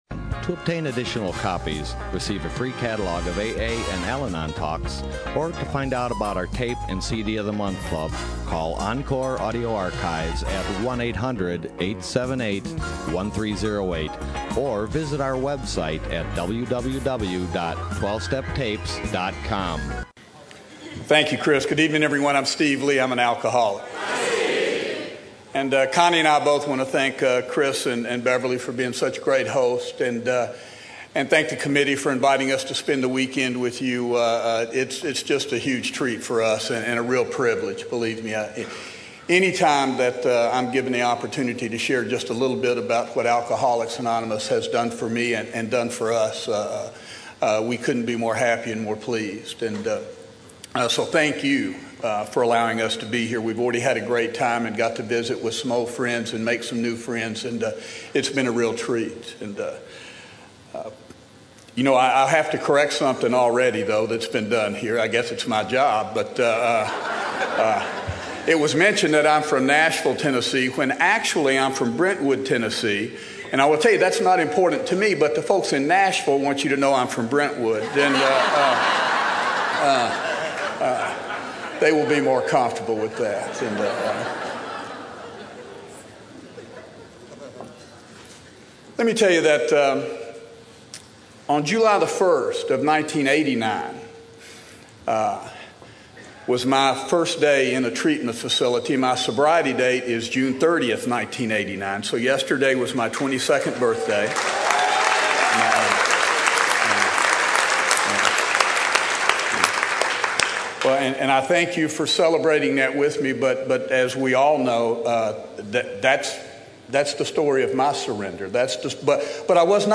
SOUTHBAY ROUNDUP 2011